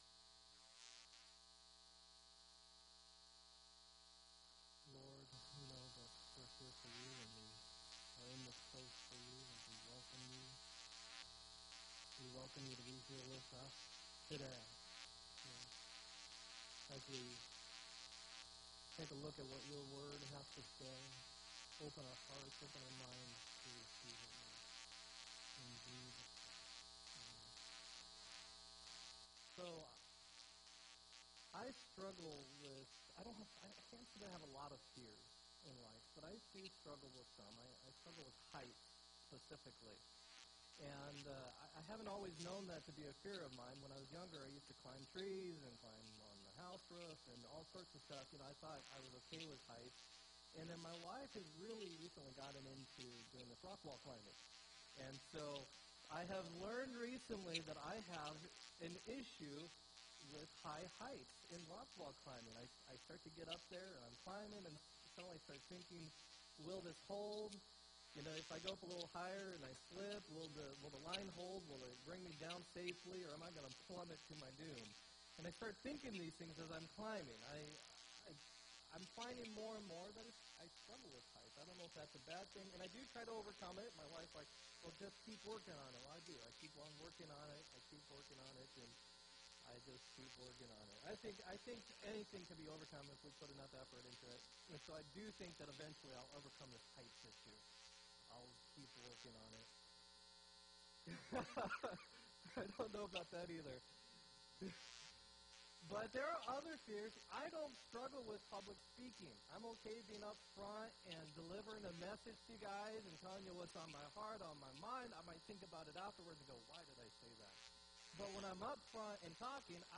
6-24-17 sermon